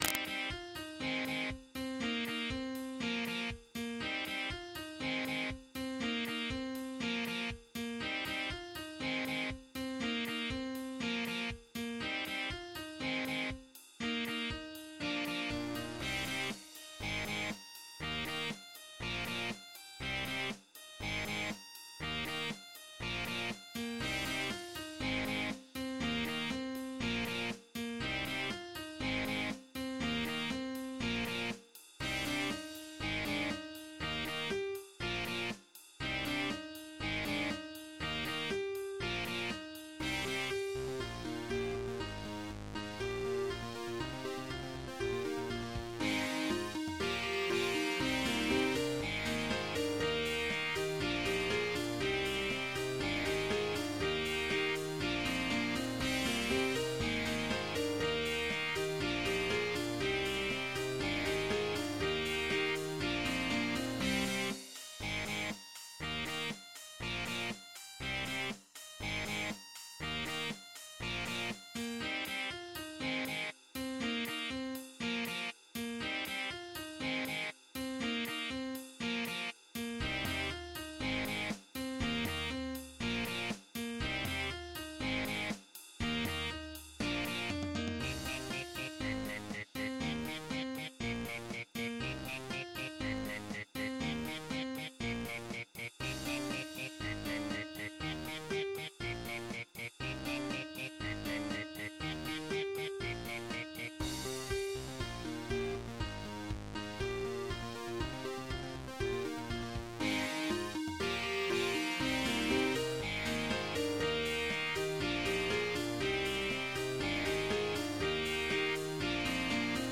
MIDI 25.9 KB MP3 (Converted) 3.65 MB MIDI-XML Sheet Music